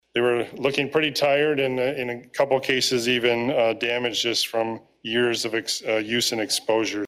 Coldwater City Manager Keith Baker told the City Council back in May the decorations were starting to show their age.